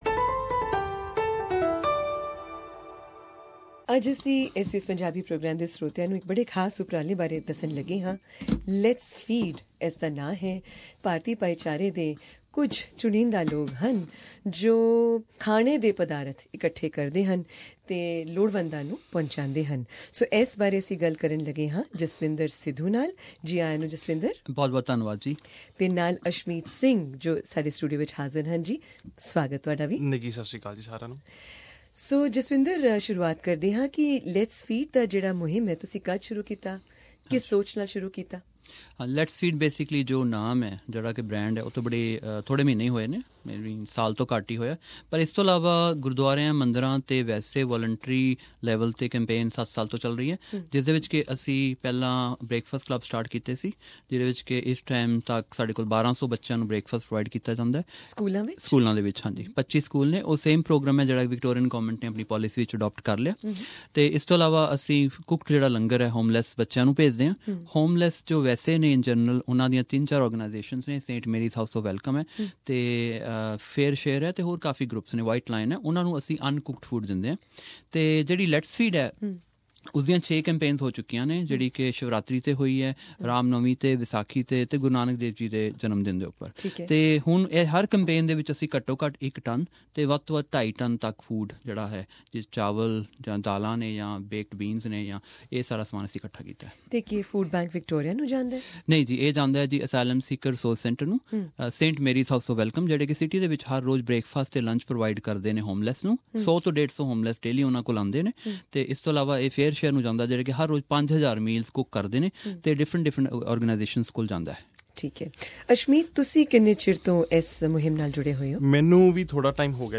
at SBS' Melbourne studios